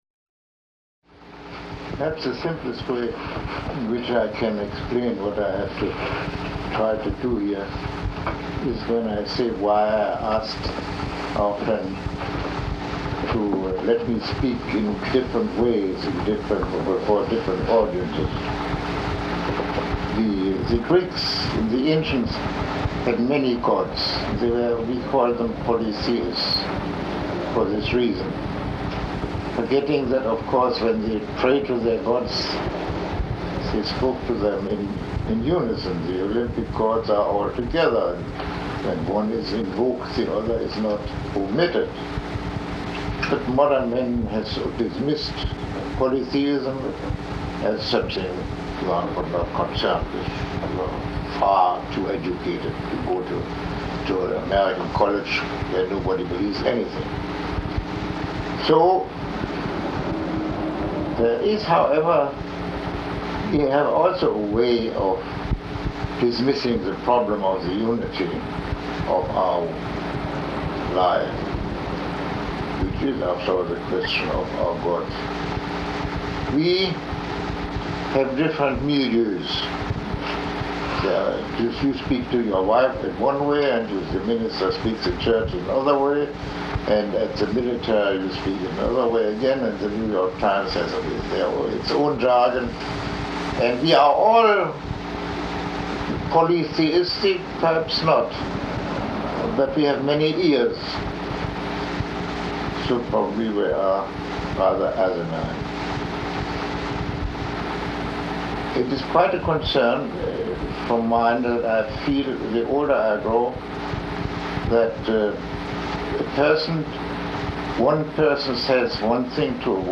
Lecture 01